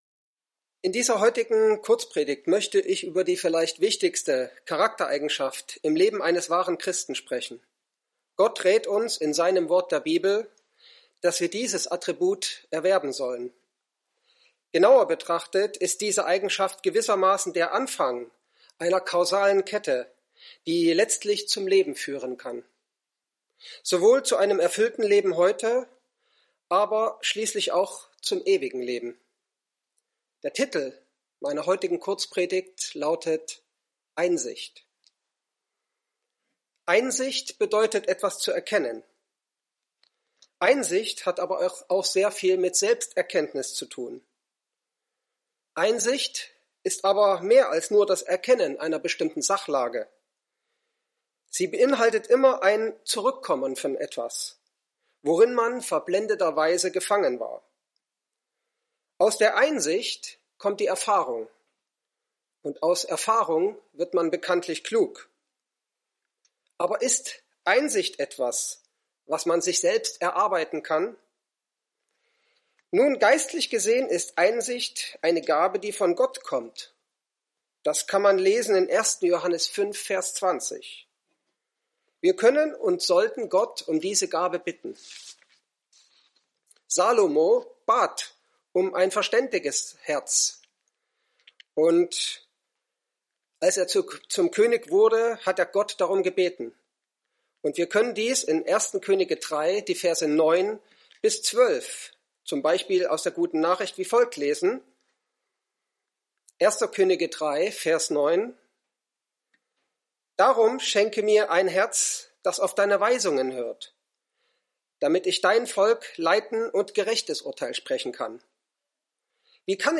In dieser Kurzpredigt geht es um die vielleicht wichtigste Eigenschaft im Leben eines wahren Christen.